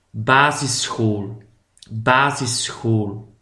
basisschool.mp3